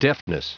Prononciation du mot deftness en anglais (fichier audio)
Prononciation du mot : deftness